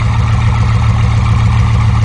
sound / acf_engines / w16medium.wav